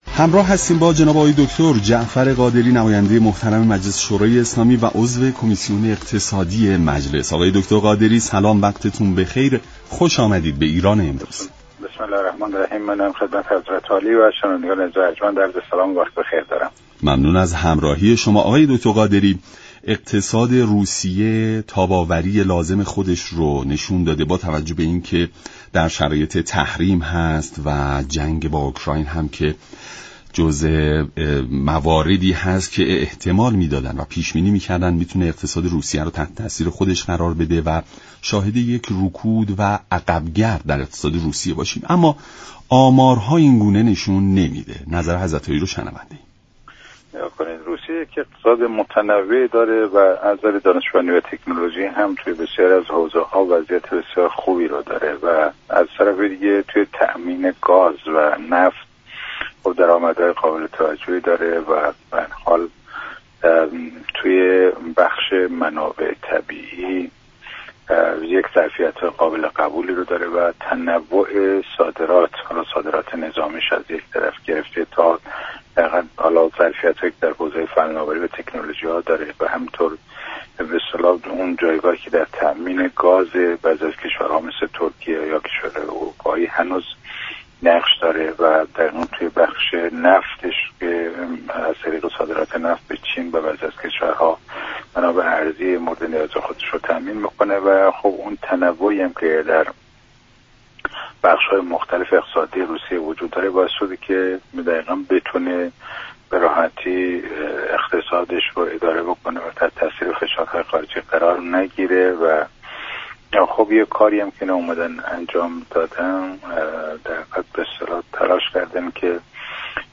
جعفر قادری عضو كمیسیون اقتصادی مجلس در ایران امروز گفت: هر چند اروپا و آمریكا تعاملات خود را با روسیه قطع كرده اند؛اما تعامل روسیه با دیگر كشورها همچنان برقرار است.